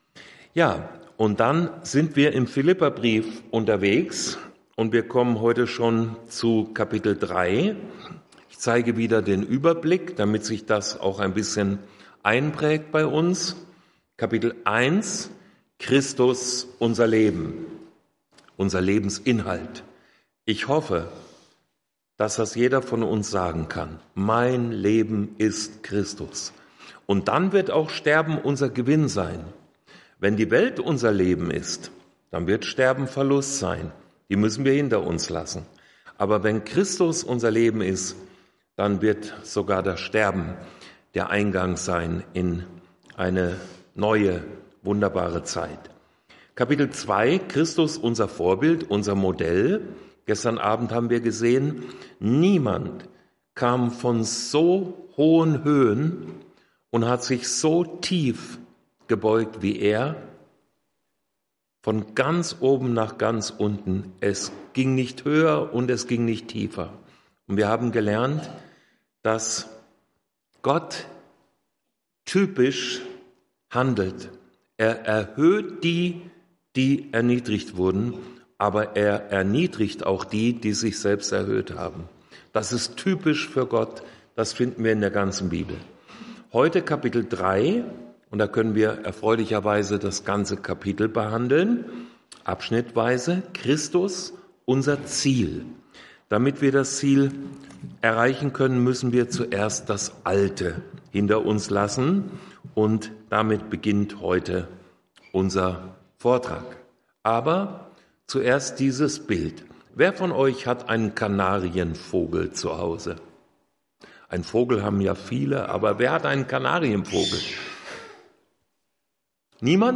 Vortragsreihe